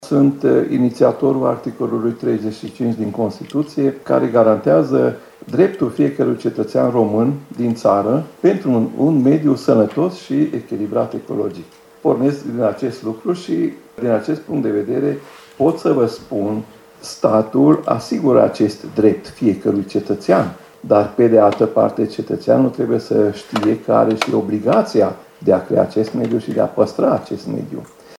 În acest sens, Radio Tg.Mureş a iniţiat şi organizat miercuri, 15 noiembrie, o dezbatere la care au participat autoritățile publice locale și județen,  dar și reprezentanţi ai ONG-urilor de profil.
Cetăţenii trebuie să ştie că pot pretinde factorilor responsabili un mediu sănătos. Constituția României prevede, la articolul 35, acest drept al cetățeanului, a subliniat viceprimarul Tîrgu-Mureșului, Makkai Grigore: